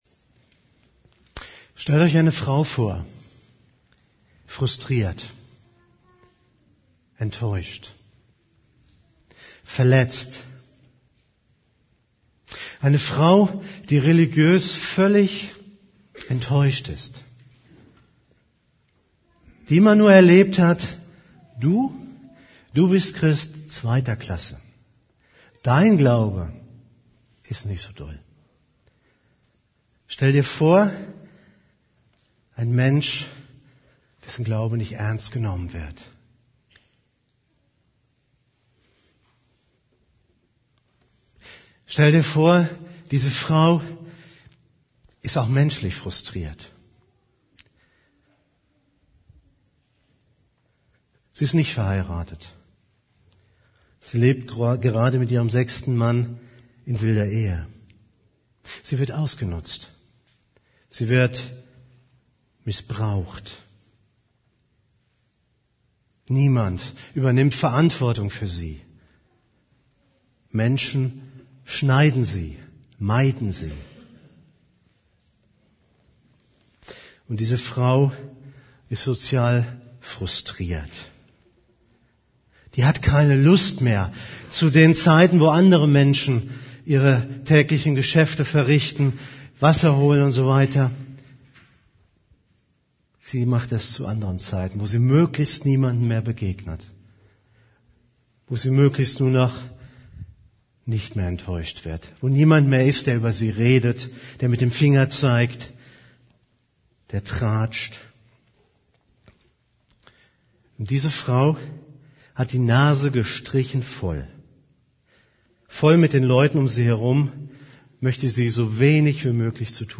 > Übersicht Predigten Jesus bietet dir Heilung deiner Persönlichkeit an Predigt vom 13.